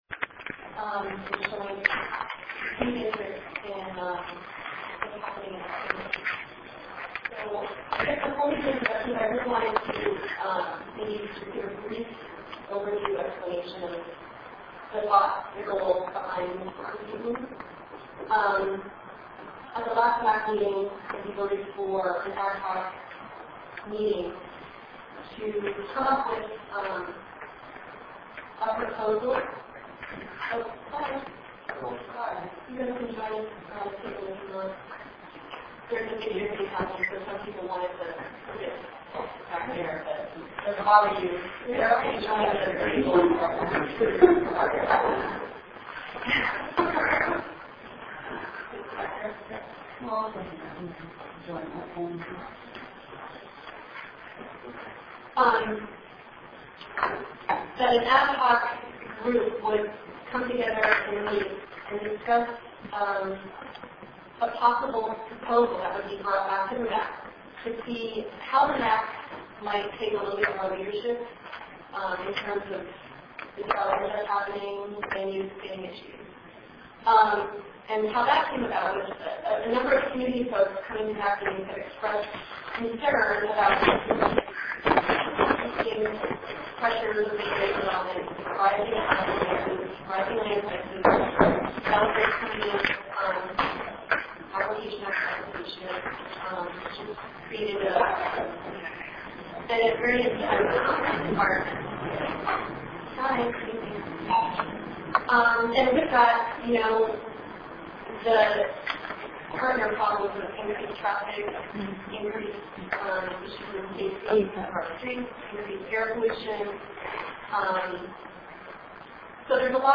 Audio files of monthly El Sobrante Municipal Advisory Council Meetings
ES MAC Special Meeting 9-29-05.mp3